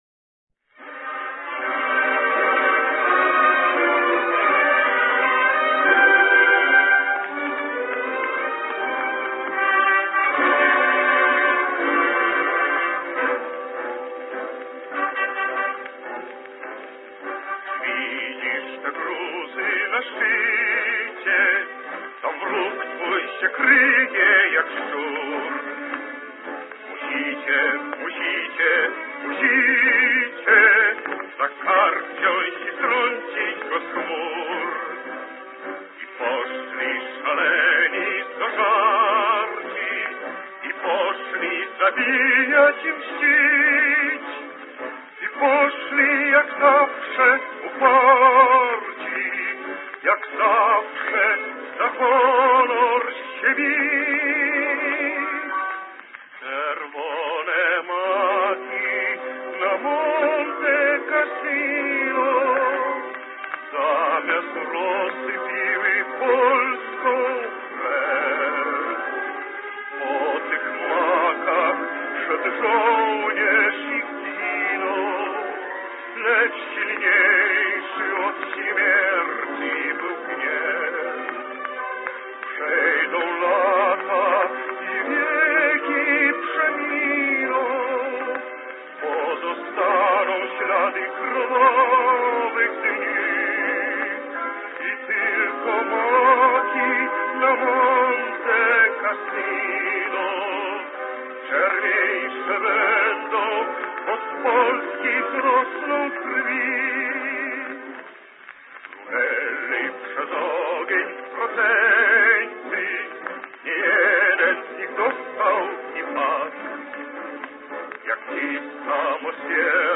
Баритон-соло